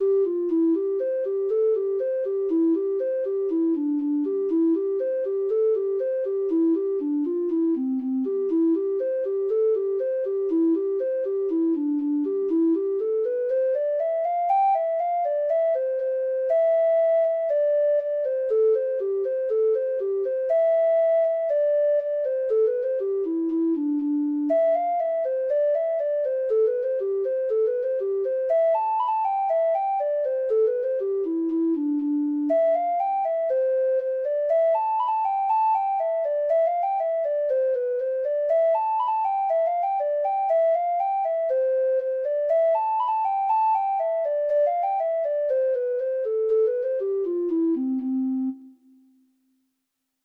Traditional Music of unknown author.
Reels
Irish